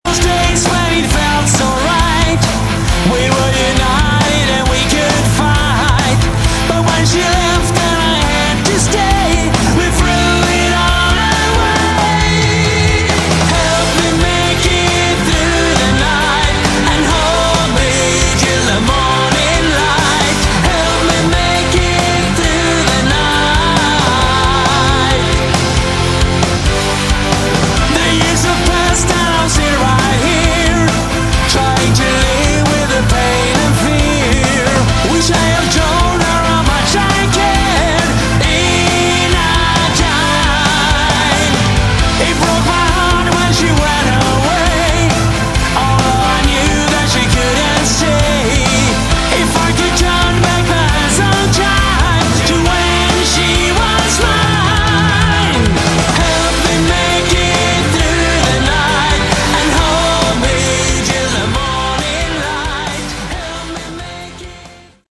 Category: Hard Rock
bass
guitar
vocals
keyboards
drums